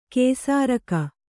♪ kēsāraka